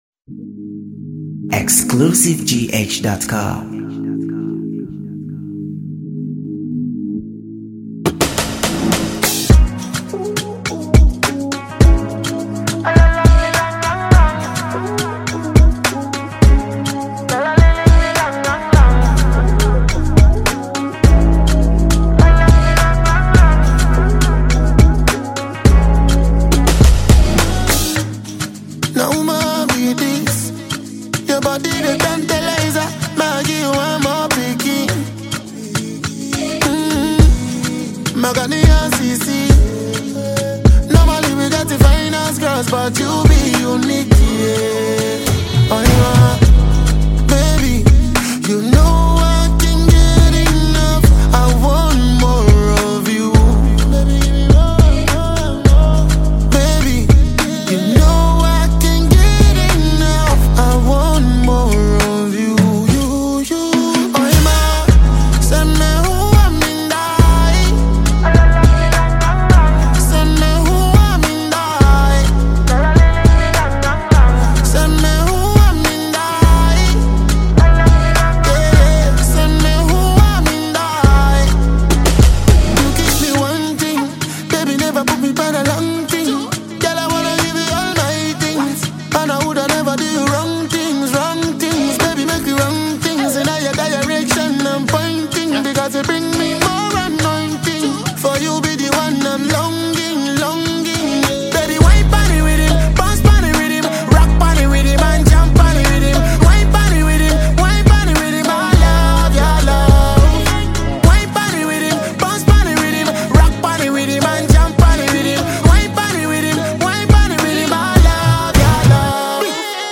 Ghanaian reggae and dancehall singer
The song has a mellow and emotional tone
With its memorable chorus and memorable beat